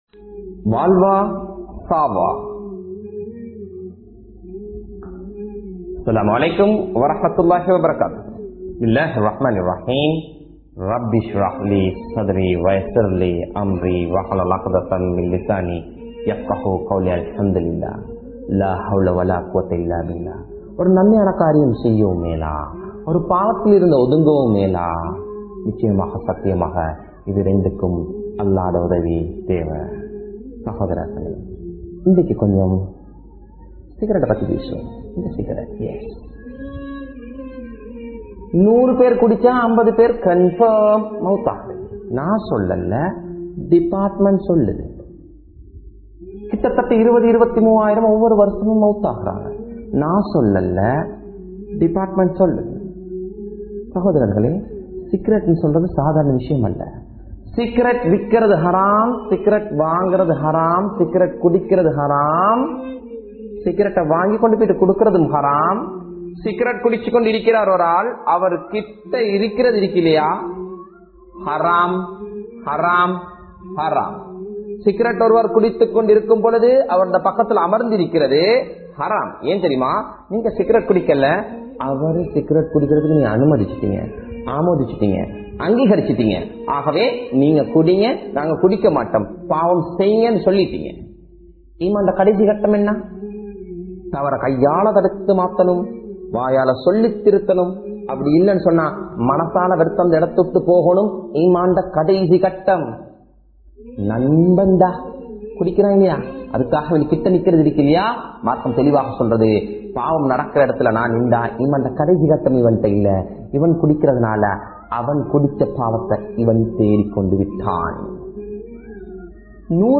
Pillaihalukku Cancer ai Uruvakkum Pettroar (பிள்ளைகளுக்கு புற்றுநோயை உருவாக்கும் பெற்றோர்கள்) | Audio Bayans | All Ceylon Muslim Youth Community | Addalaichenai